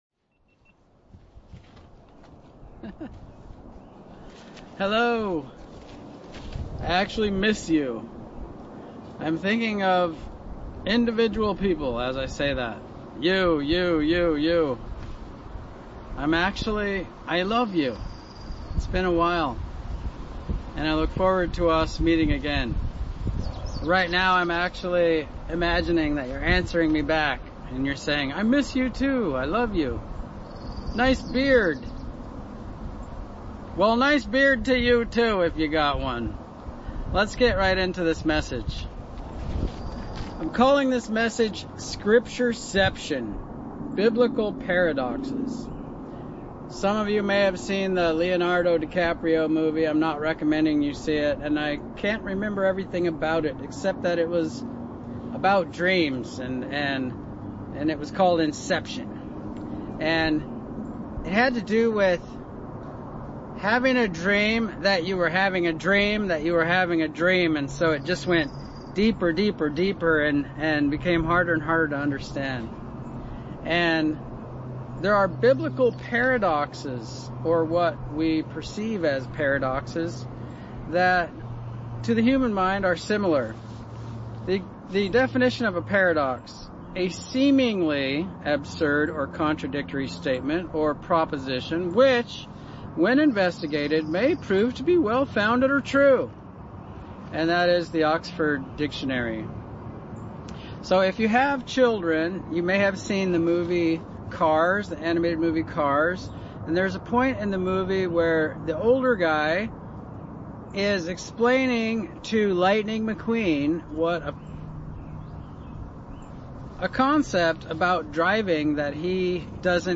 Sermons
Given in Central Oregon Medford, OR